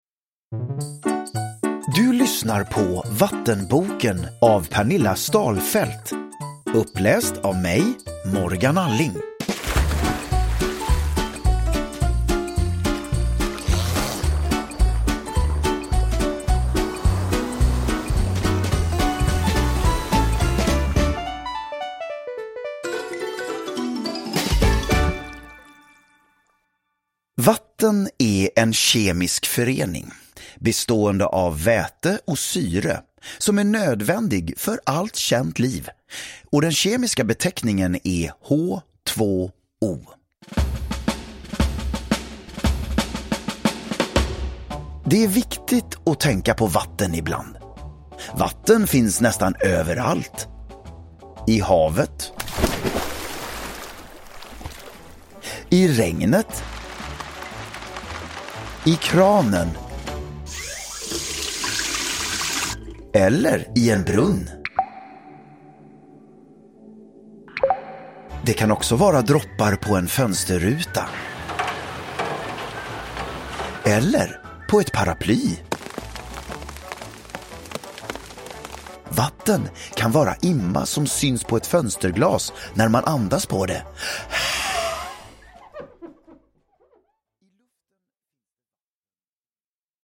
Vattenboken – Ljudbok
Uppläsare: Morgan Alling